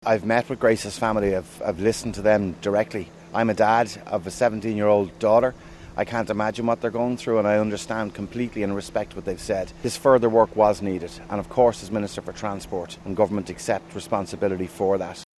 Transport Minister Darragh O’Brien hopes the new regulations will be a small consolation for the family: